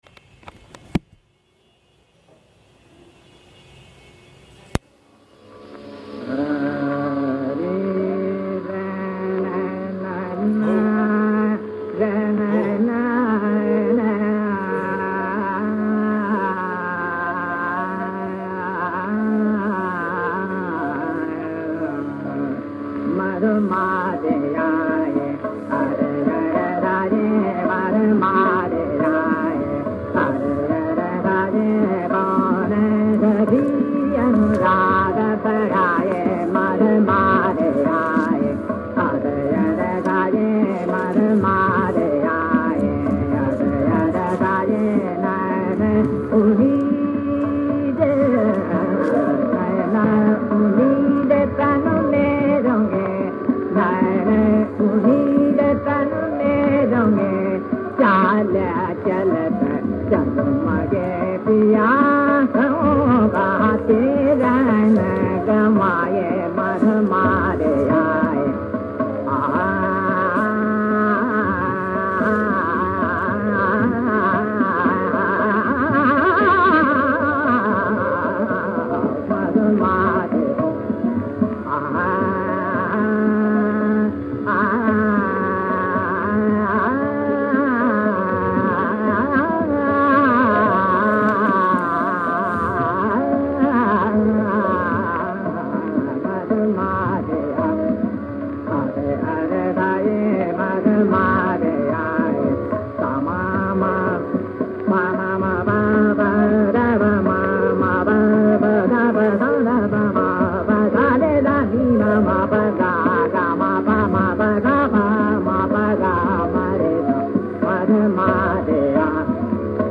उनके कुछ ही ऑडियो क्लिप विनाइल डिस्क या रेडियो कार्यक्रमों में रिकॉर्ड किए गए हैं, और संभवतः 1940 या 1950 के दशक में वे रिकॉर्ड किए गए थे।